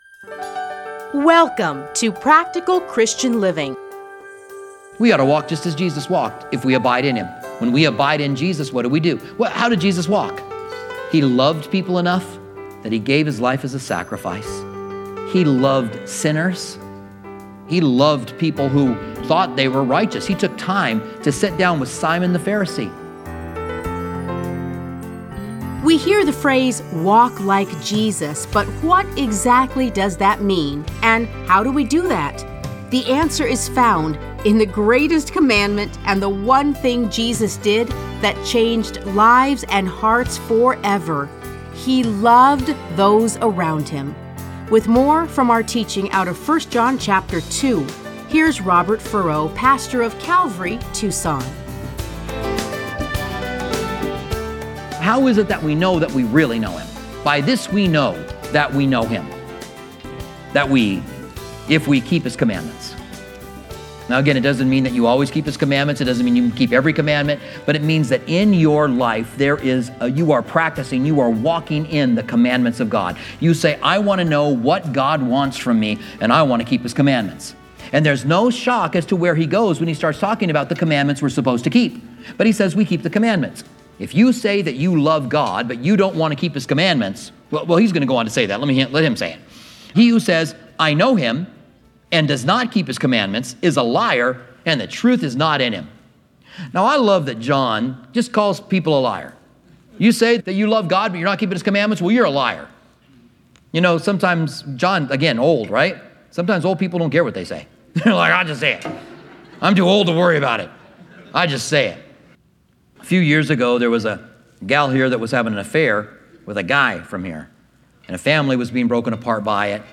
Listen to a teaching from 1 John 2:1-11.